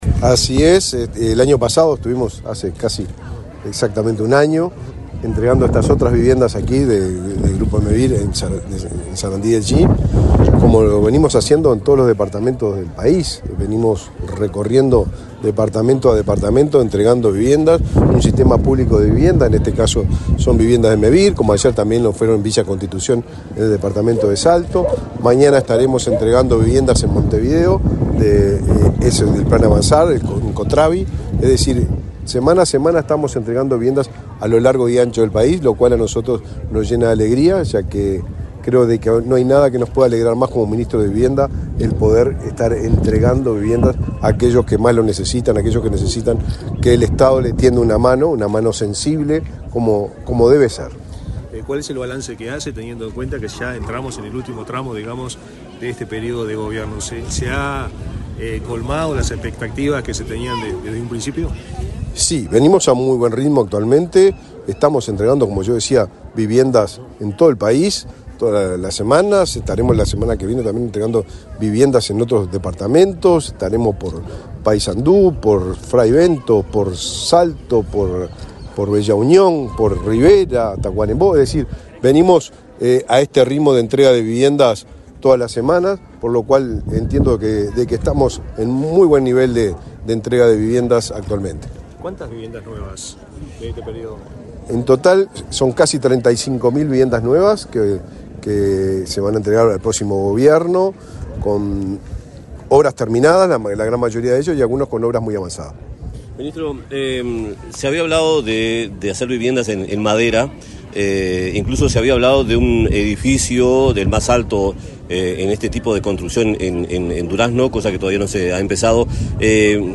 Este miércoles 25, el ministro de Vivienda, Raúl Lozano, fue entrevistado para medios periodísticos en Durazno, donde Mevir inauguró 54 soluciones